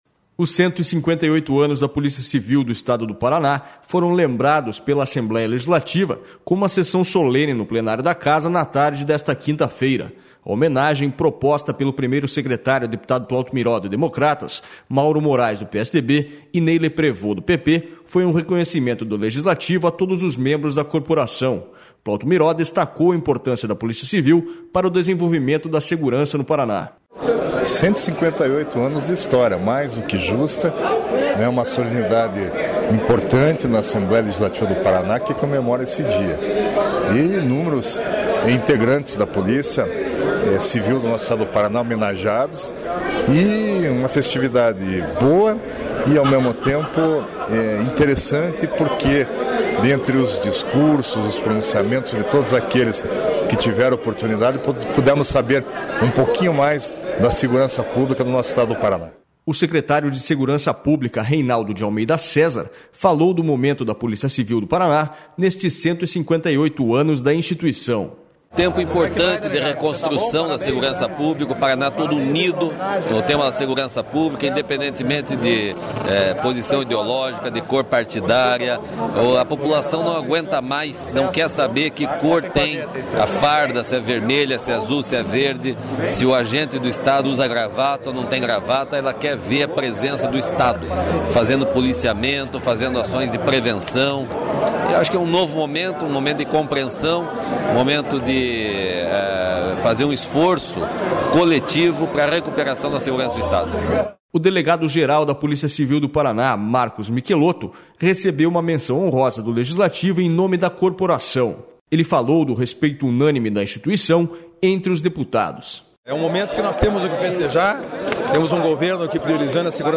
Os 158 anos da Polícia Civil do Estado do Paraná foram lembrados pela Assembleia Legislativa com uma sessão solene no Plenário da Casa na tarde desta quinta-feira.//A homenagem, proposta pelo primeiro secretário, deputado Plauto Miró, do DEM, Mauro Moraes, do PSDB, e Ney Leprevost, do PP, foi um rec...